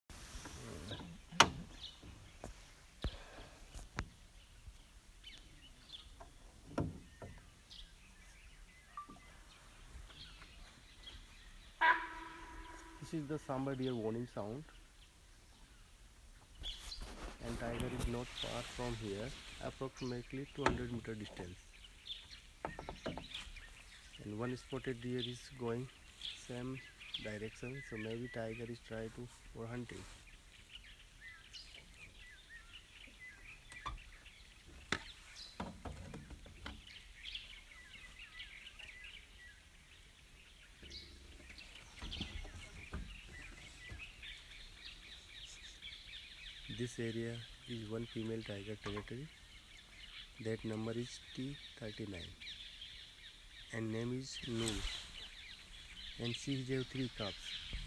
Halfway into our 3 hour safari, we saw female Bengal tiger tracks in the road.  Then further on, we could hear a tiger barking a warning signal.
tiger-2.m4a